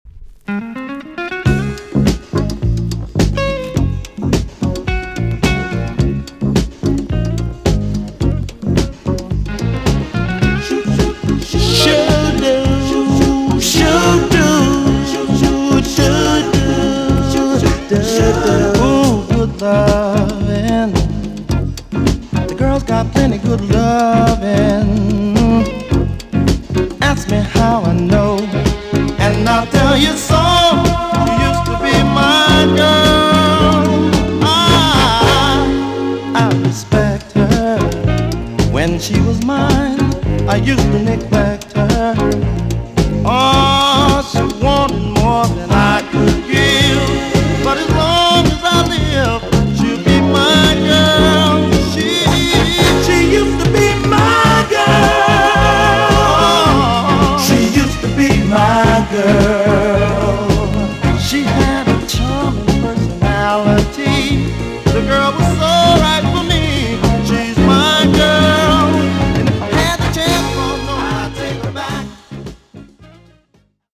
EX 音はキレイです。
JAMAICAN SOUL RECOMMEND!!
NICE MELLOW MODERN SOUL TUNE!!